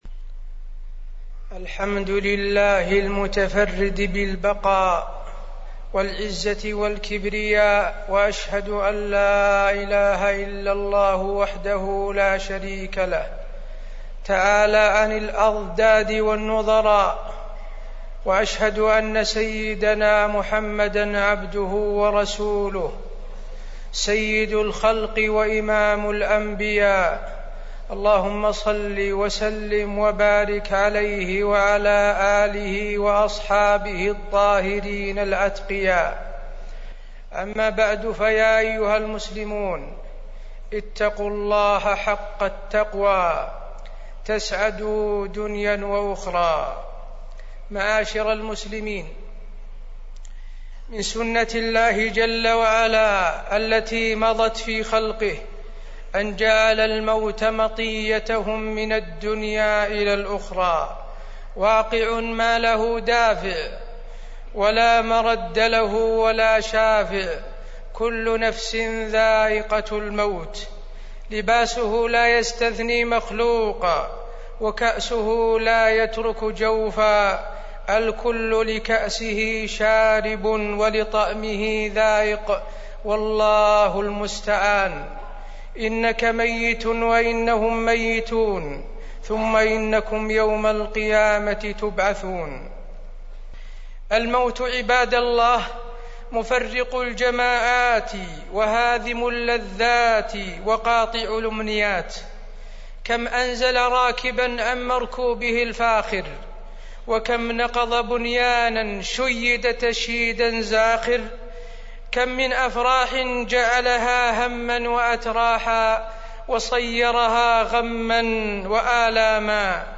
تاريخ النشر ٣٠ جمادى الآخرة ١٤٢٦ هـ المكان: المسجد النبوي الشيخ: فضيلة الشيخ د. حسين بن عبدالعزيز آل الشيخ فضيلة الشيخ د. حسين بن عبدالعزيز آل الشيخ وفاة الملك فهد